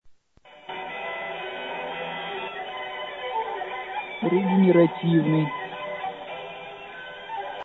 Так звучит регнеративное радио на том же самом сигнале